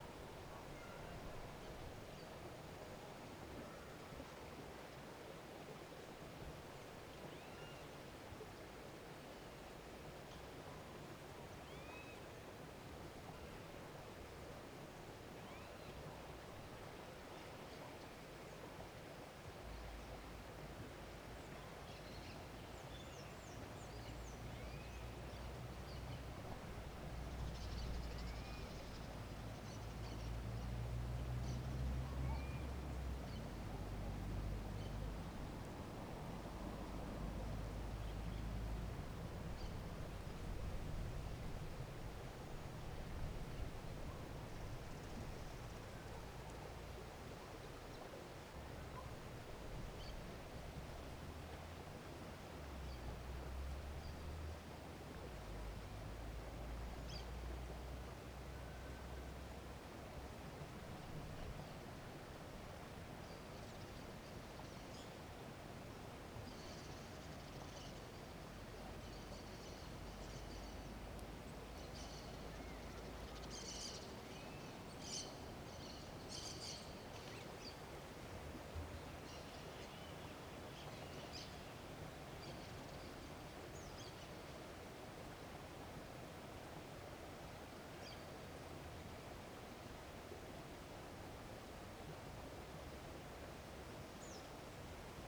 CSC-03-126-OL- Ambiente de descampado longe da Vila.wav